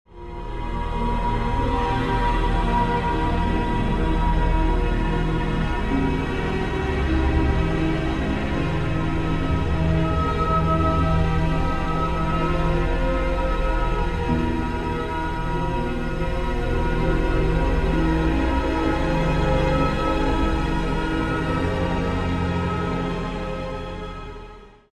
a dream-like soundtrack